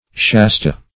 Shasta \Shas"ta\, n.